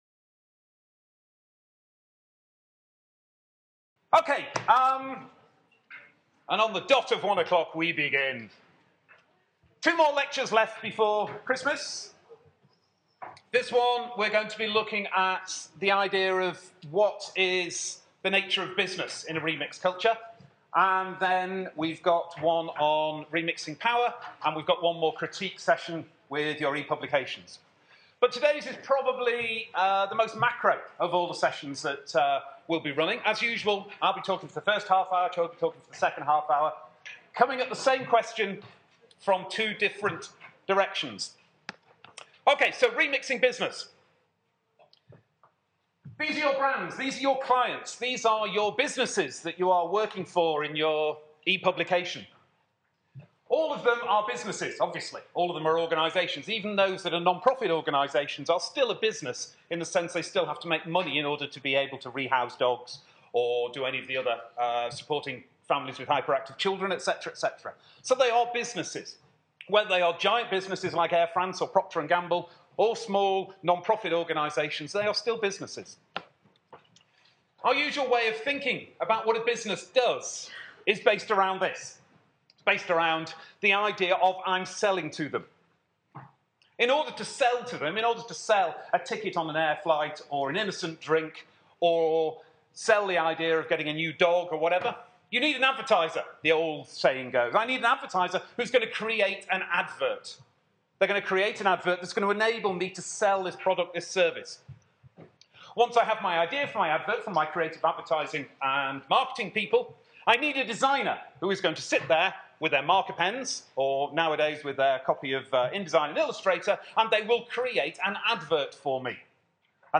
Remixing business lecture